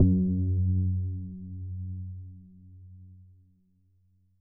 SPOOKY F#1.wav